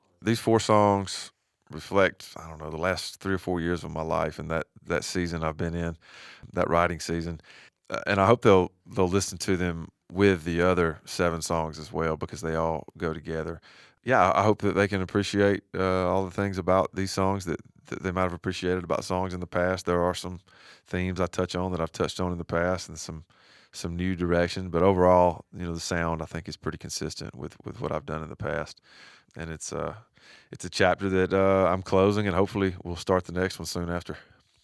Sam Hunt talks about what he hopes for fans listening to his new Locked Up EP.